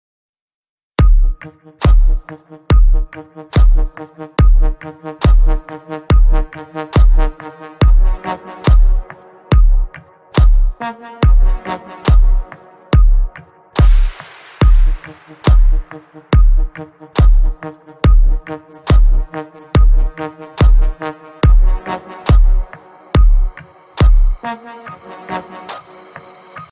Рингтоны Без Слов
Рингтоны Ремиксы » # Рингтоны Электроника